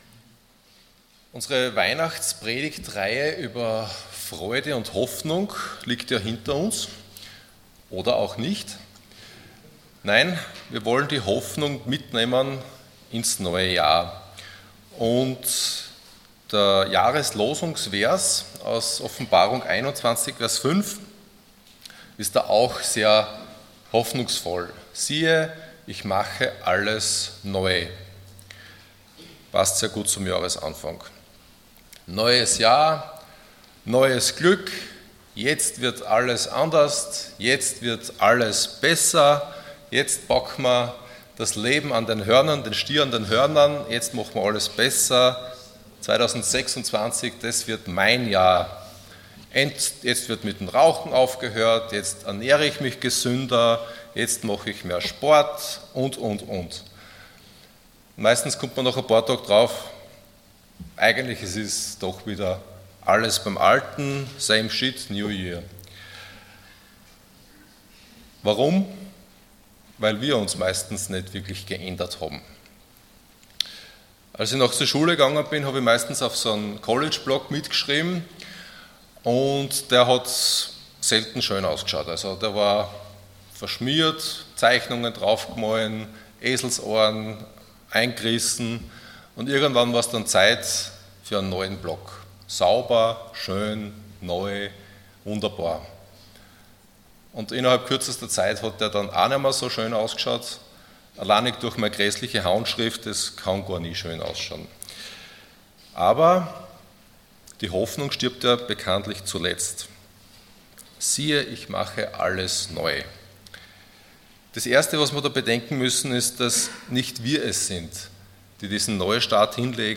Predigt zur Jahreslosung 2026 aus Offenbarung 21,5.